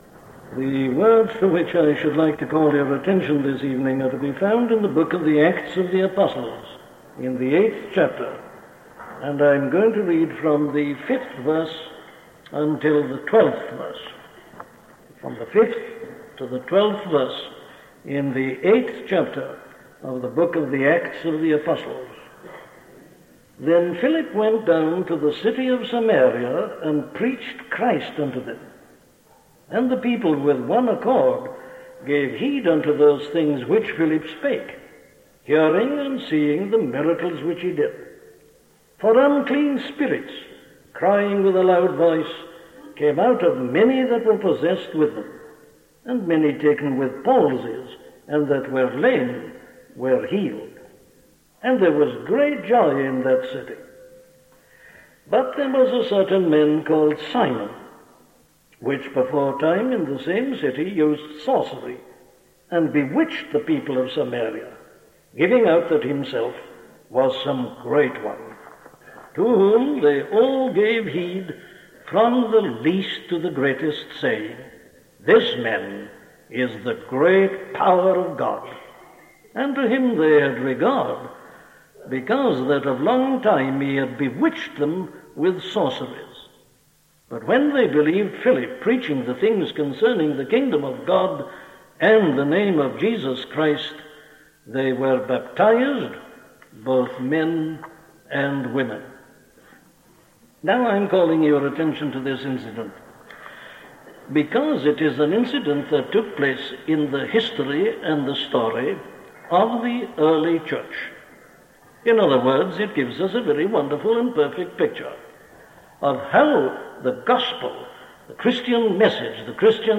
Principalities and Powers - a sermon from Dr. Martyn Lloyd Jones
Listen to the sermon on Acts 8:5-12 'Principalities and Powers' by Dr. Martyn Lloyd-Jones